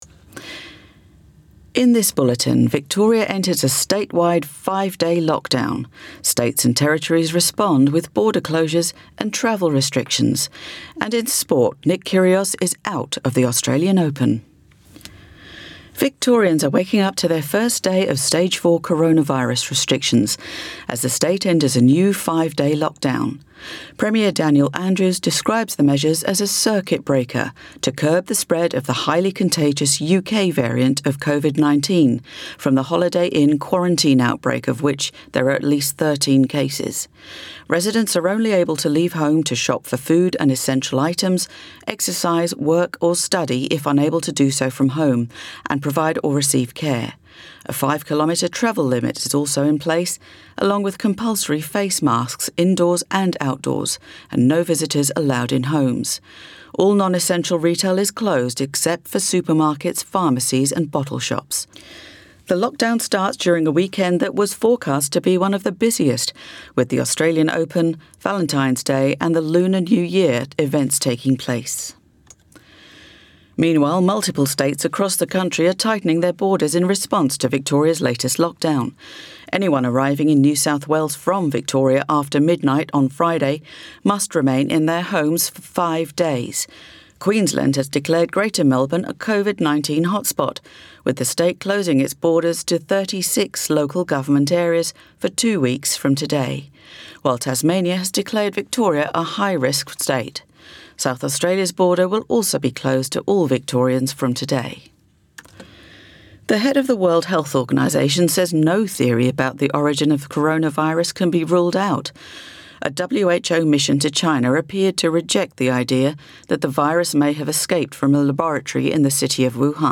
AM bulletin 13 February 2021